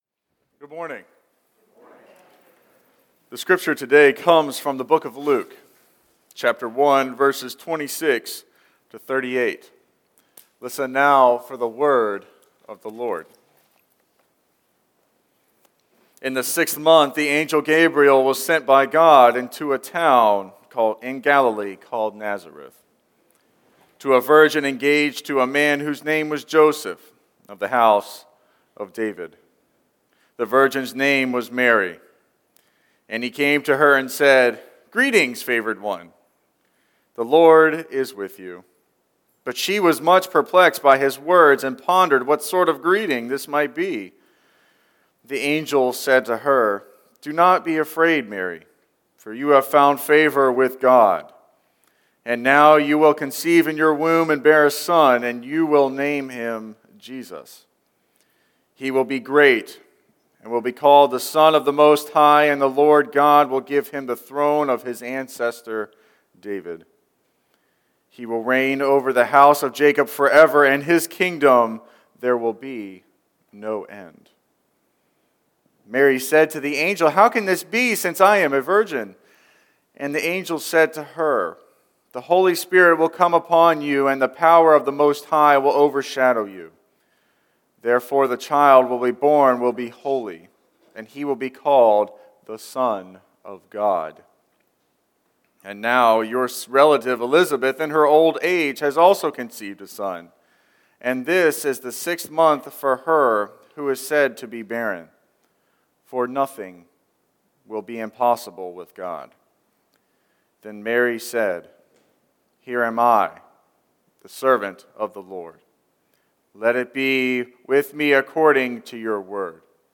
2nd Sunday of Advent
“Dont Lose Heart” Listen to this Week’s Sermon Posted in
12-04-Scripture-and-Sermon.mp3